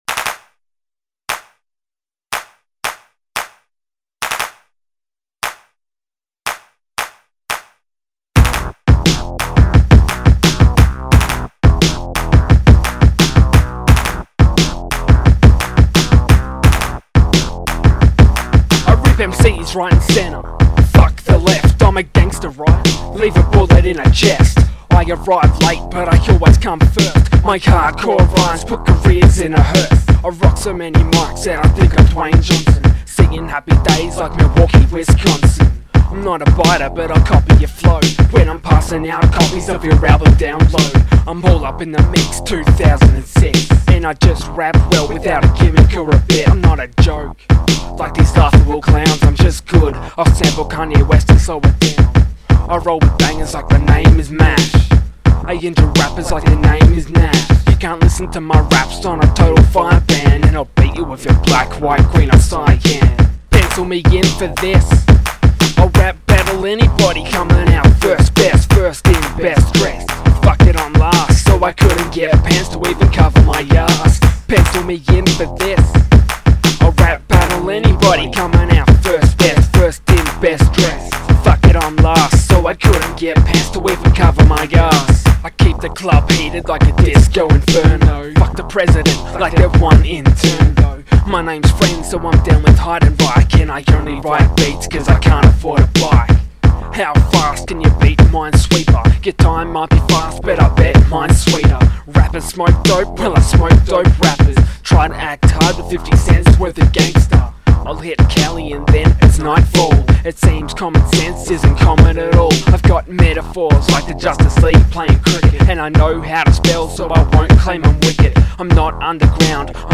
Must Include Handclaps
Nice squealchy synth-bass.
Cool mix of beats and stuff.
Accent is pretty cool